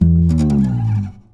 52-str10-abass-fx.aif